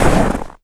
High Quality Footsteps
STEPS Snow, Run 01.wav